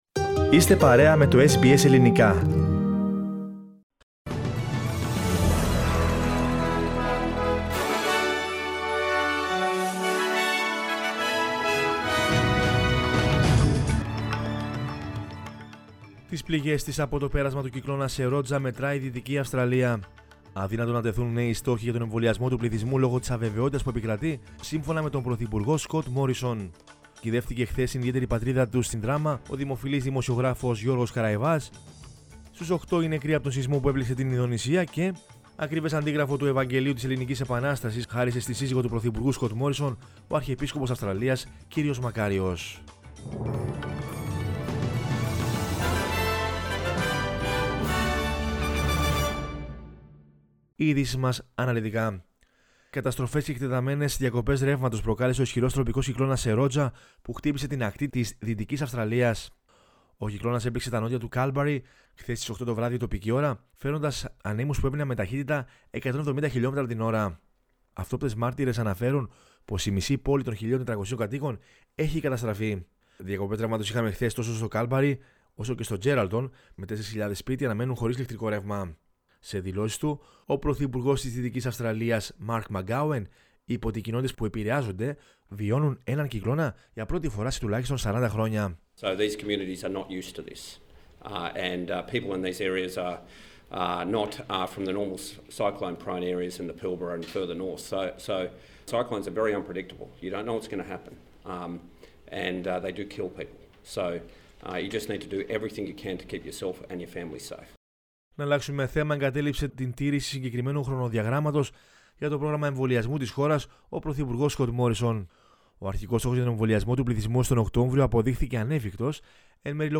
News in Greek from Australia, Greece, Cyprus and the world is the news bulletin of Monday 12 April 2021.